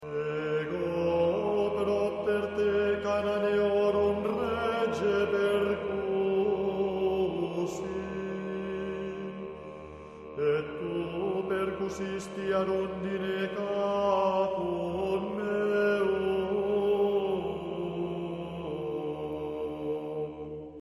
Esecuzione sulla scorta di codici del rito romano antico.
La seconda parte ribadisce gli stessi concetti sopra espressi ma suddivisi in nove improperia (cantati dai soli su modello salmodico) intercalati dal coro che ripete ogni volta i primi versi con cui aveva esordito nella prima parte: "Popule meus, quid feci tibi? Aut in quo contristavi te? Responde mihi!":